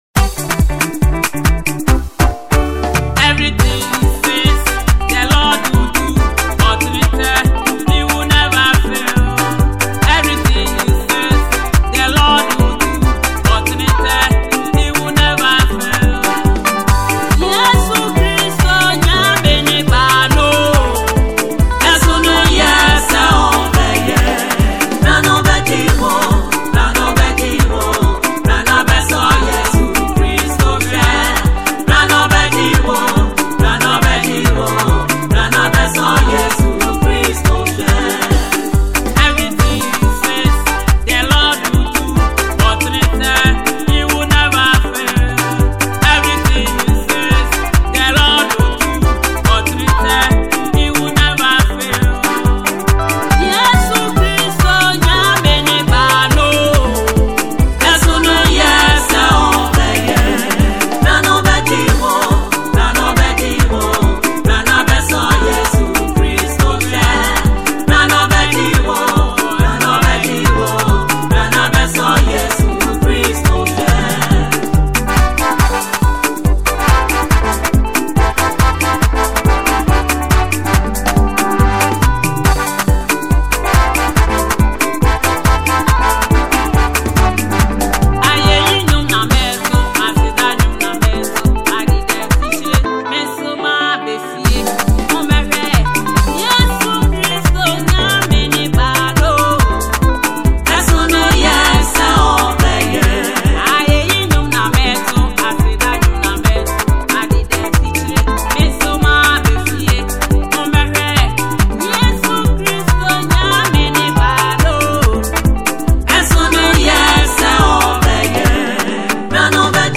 Gospel
Fast rising Ghanaian Gospel singer, songwriter and musician